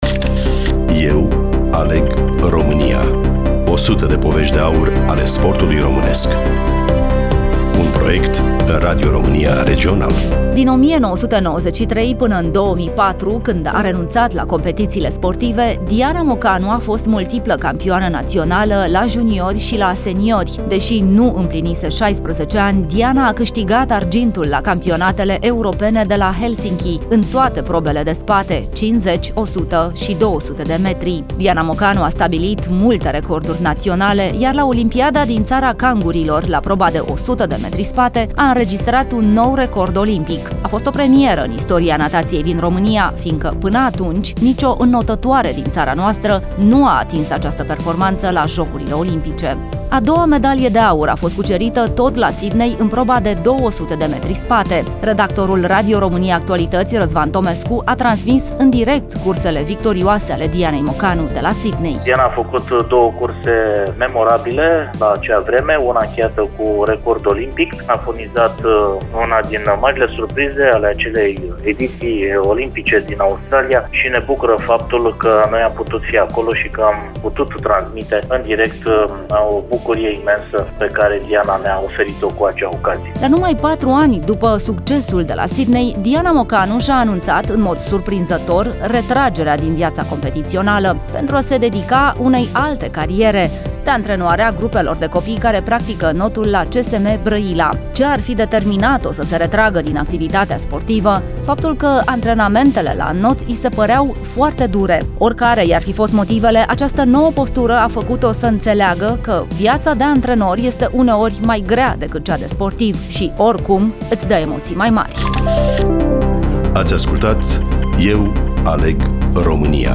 Studioul Radio România Constanța